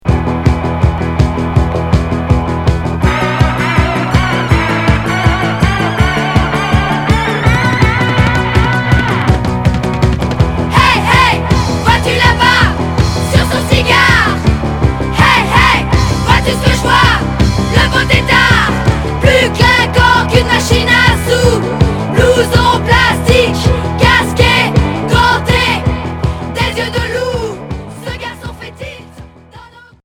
Punksploitation